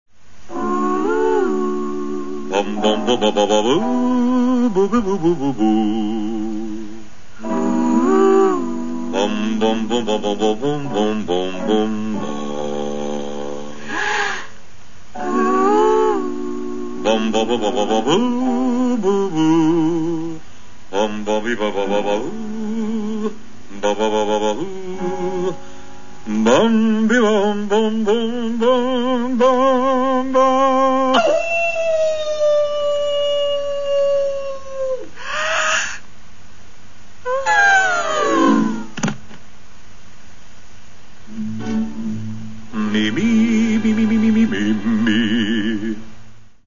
- The townspeople singing about Ichabod.